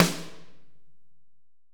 Index of /90_sSampleCDs/AKAI S6000 CD-ROM - Volume 3/Drum_Kit/AMBIENCE_KIT3
ST2AMBSN7 -S.WAV